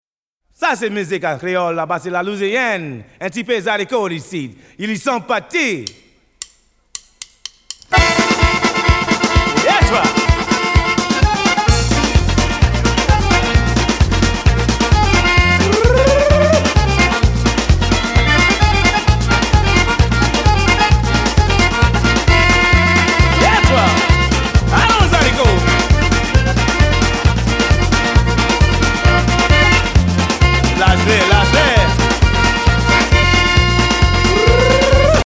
Genre: Country & Folk.